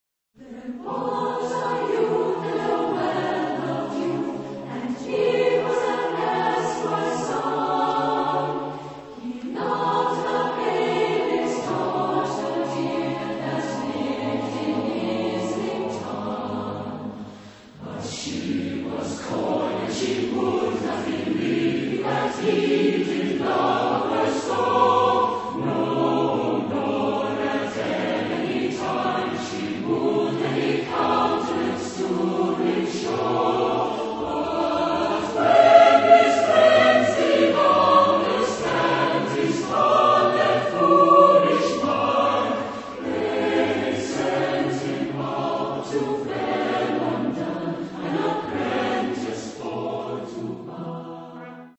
Type de choeur : SATB  (4 voix mixtes )